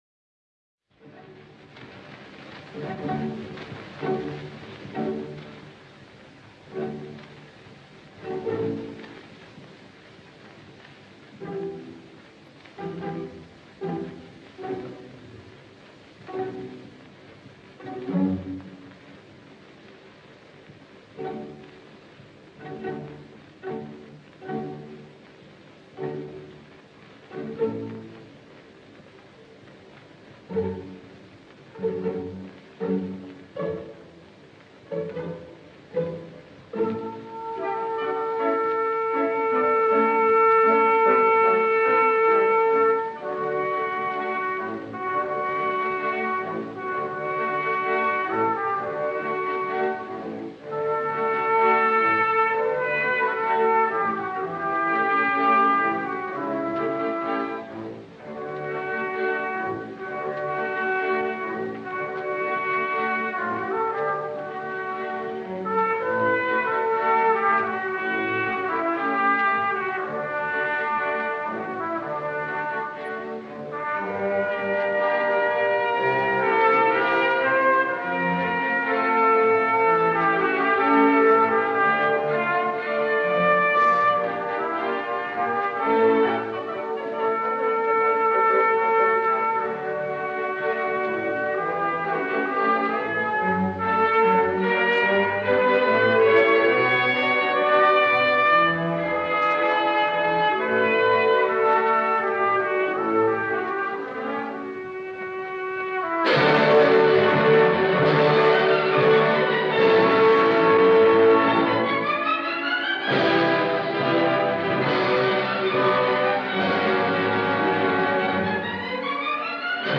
opera completa, registrazione in studio.